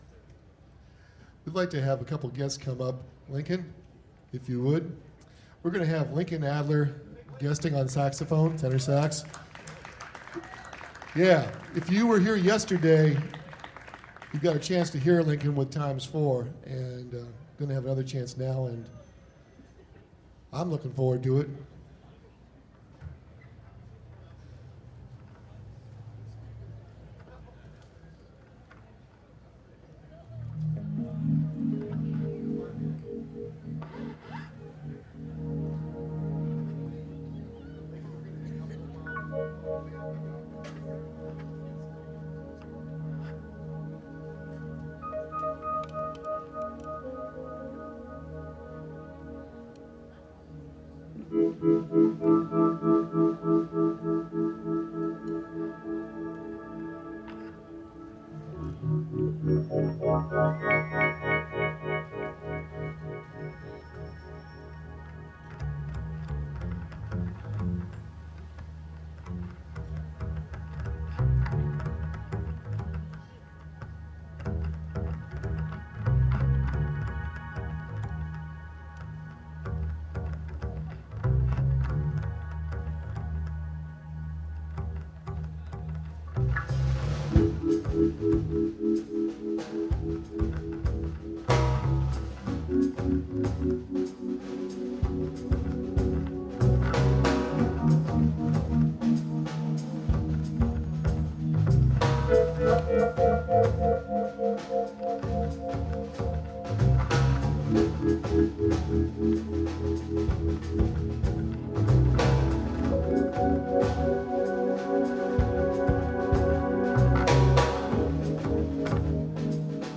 Bass
Keys
Drums
Tenor
Vocals